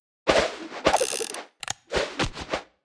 Media:Sfx_Anim_Ultimate_Colt.wav 动作音效 anim 在广场点击初级、经典、高手、顶尖和终极形态或者查看其技能时触发动作的音效
Sfx_Anim_Ultimate_Colt.wav